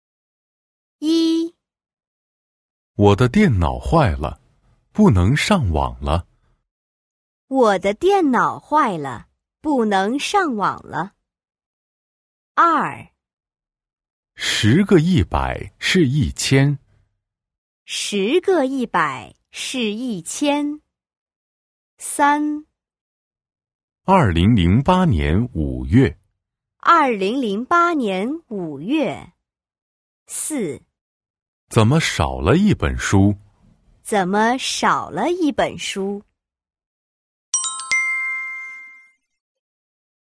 Kỹ năng nghe